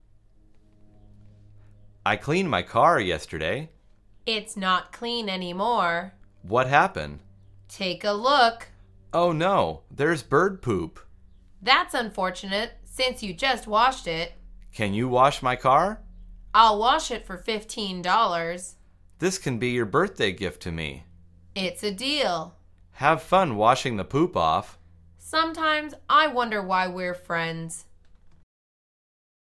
مجموعه مکالمات ساده و آسان انگلیسی – درس شماره بیستم از فصل رانندگی: مدفوع پرنده